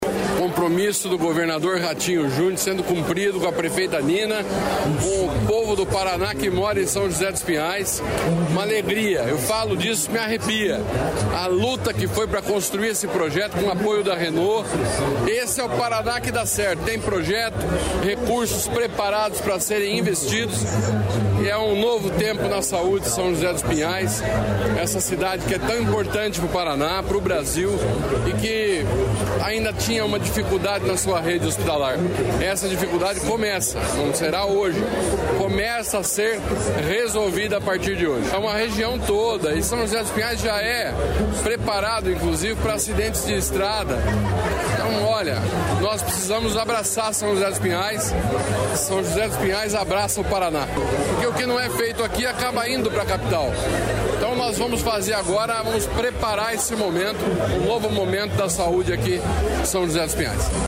Sonora do secretário da Saúde, Beto Preto, sobre o anúncio da liberação de recursos para hospital em São José dos Pinhais